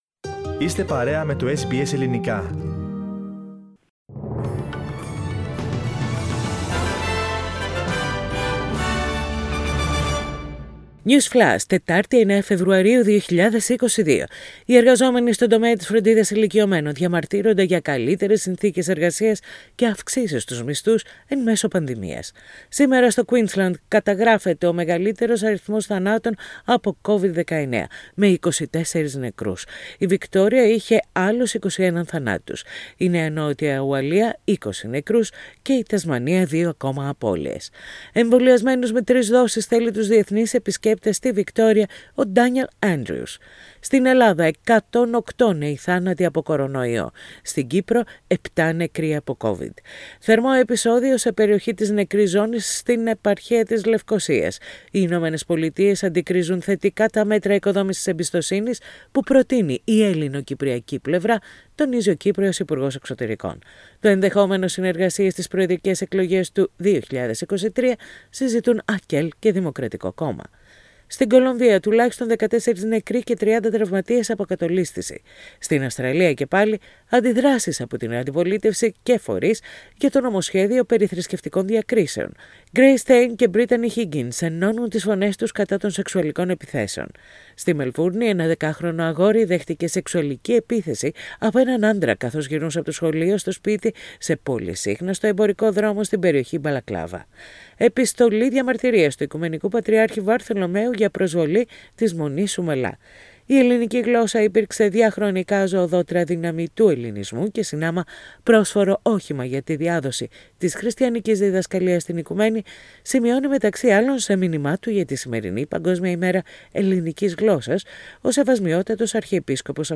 Οι ειδήσεις της ημέρας σε τίτλους, με τα νέα από Αυστραλία, Ελλάδα, Κύπρο και την διεθνή επικαιρότητα.